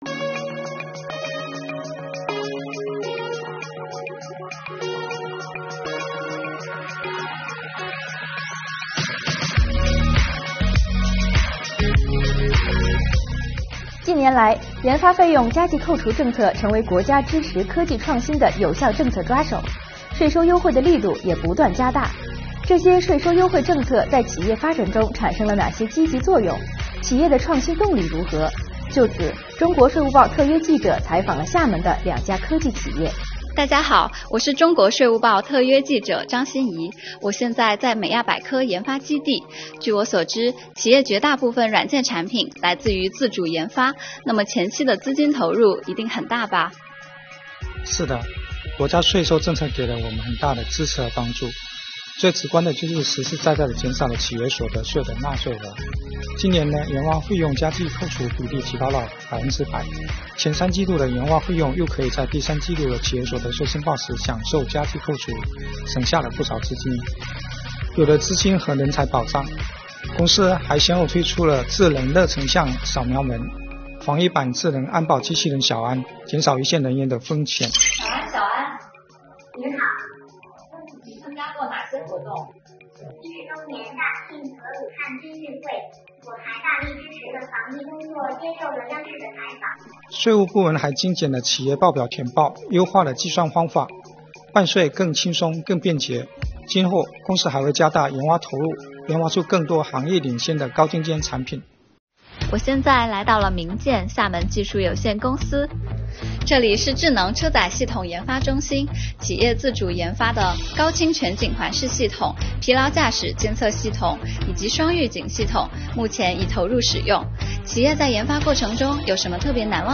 就此，中国税务报特约记者采访了厦门市的两家科技企业，听他们讲述创新路上的税收故事↑↑↑